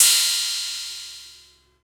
• Drum Crash F Key 21.wav
Royality free drum crash sample tuned to the F note.
drum-crash-f-key-21-pLK.wav